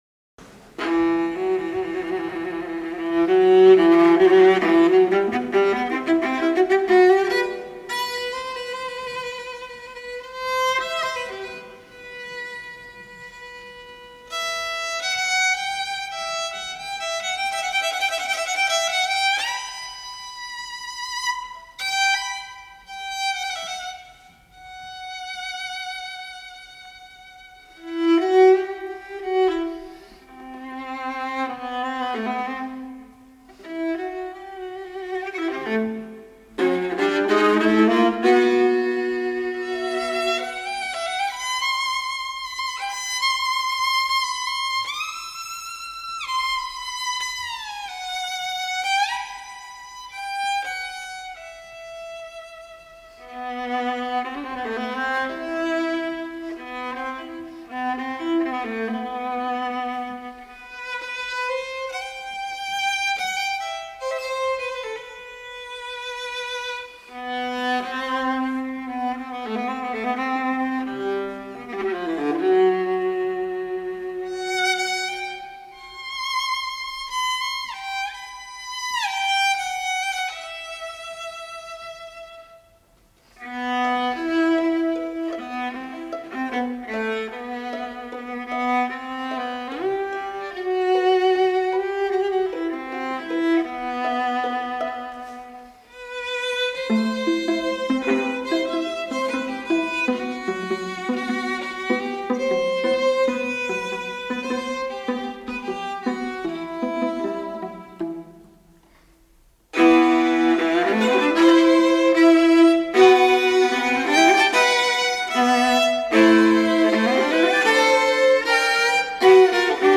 for violin and viola
for mezzo and viola